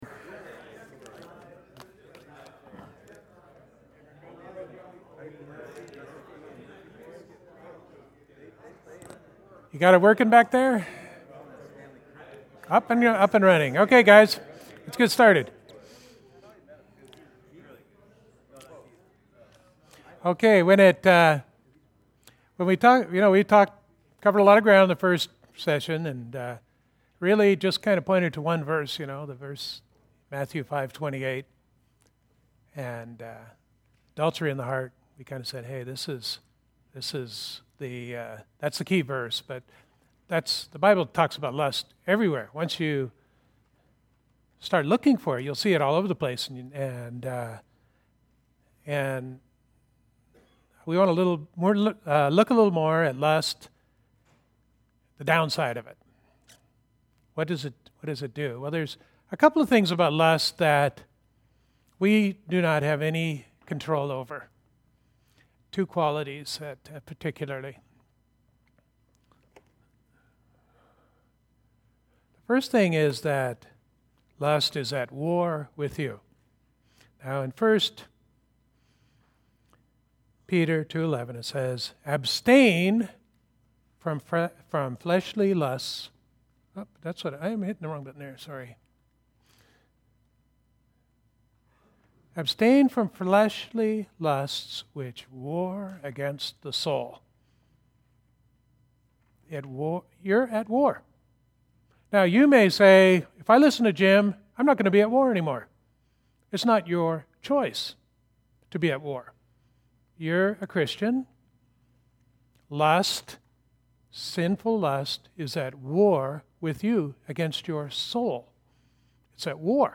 These audio files are provided here for group or individual use and were recorded at Shoreline Calvary Chapel in Morro Bay, California.